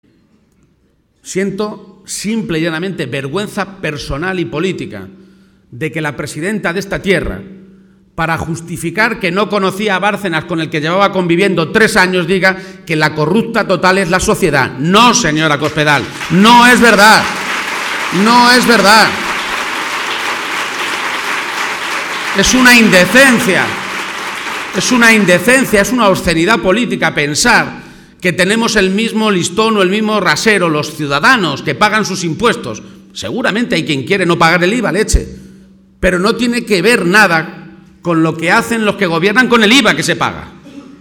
García-Page se pronunciaba de esta manera esta tarde, en Toledo, en un acto ante más de 800 personas en el que estaba acompañado por la Presidenta de Andalucía y secretaria general socialista en esa comunidad, Susana Díaz.
Audio García-Page acto PSOE Toledo 1